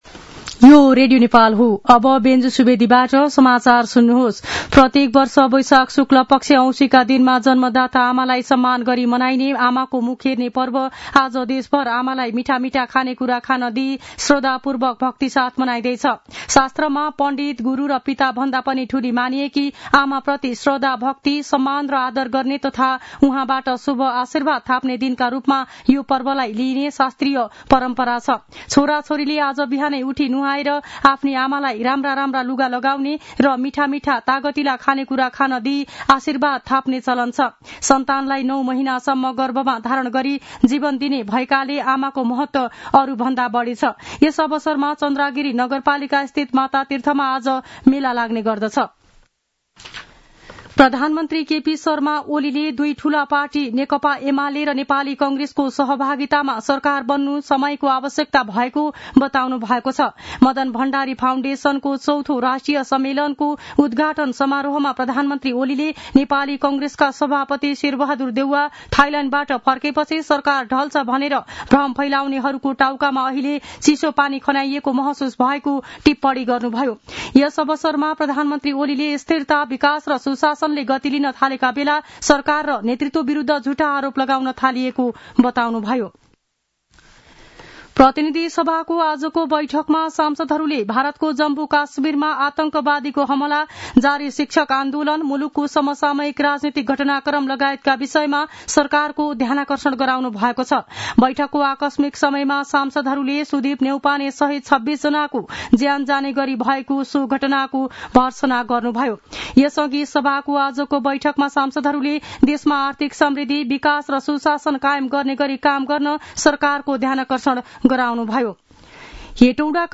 दिउँसो ४ बजेको नेपाली समाचार : १४ वैशाख , २०८२
4-pm-news-1-10.mp3